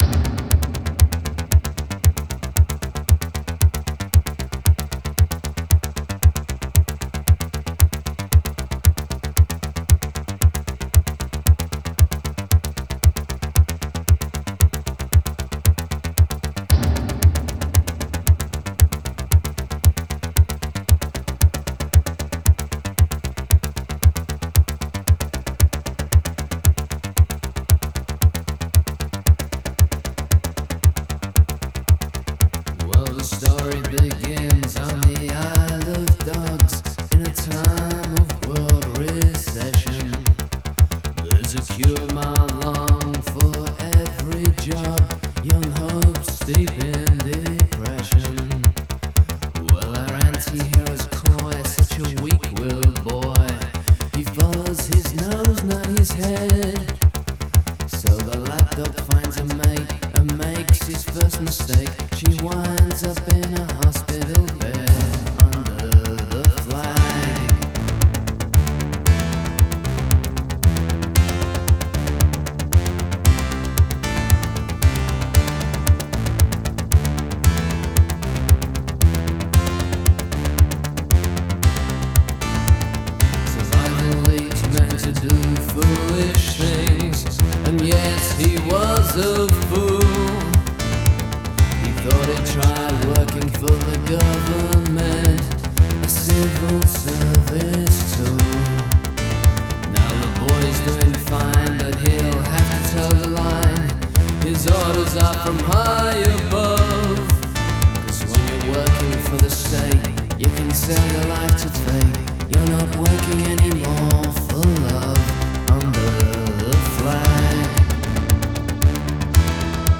mega mélange d'un mega album d'un méga artist